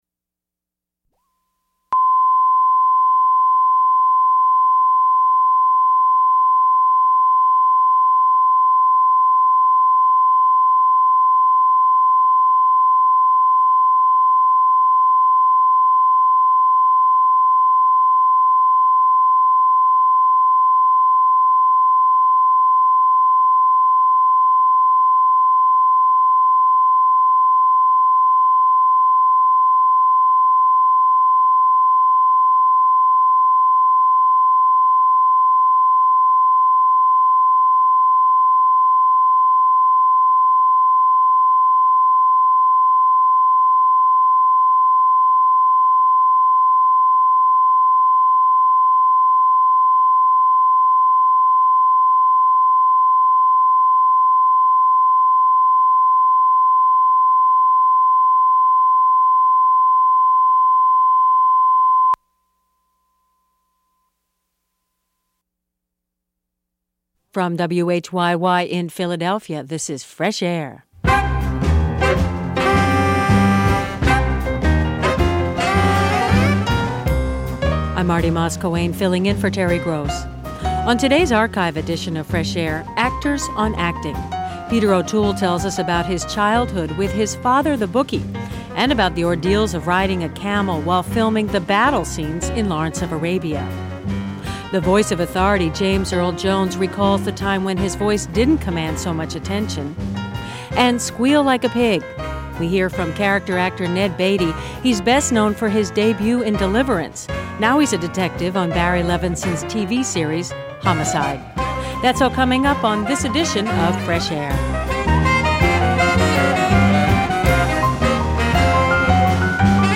His is one of the distinctive voices of our time, yet few people know he fights a stutter; Jones' stage work off-Broadway in Jean Genet's "The Blacks" and Athol Fugard's "The Blood Knot" lead to a Broadway success in "The Great White Way", for which Jones won a Tony.